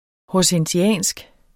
Udtale [ hɒsənˈɕæˀnsg ]